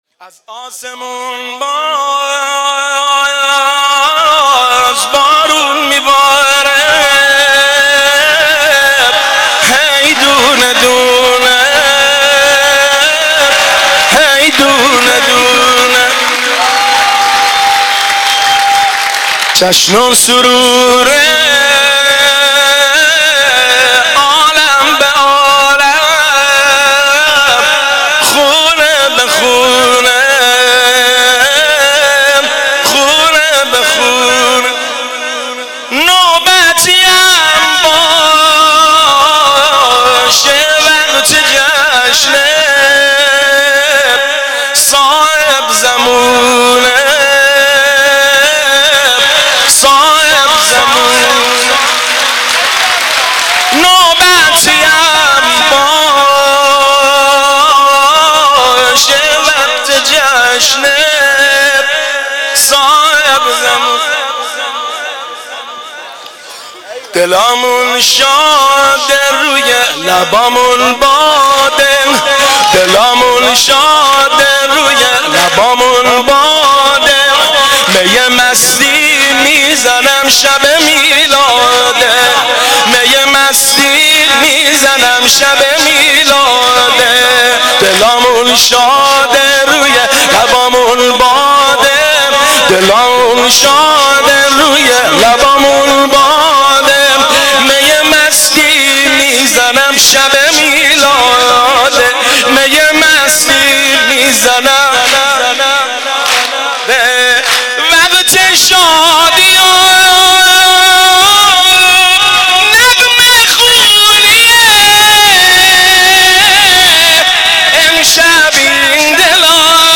مناسبت : ولادت حضرت مهدی عج‌الله تعالی‌فرج‌الشریف
قالب : سرود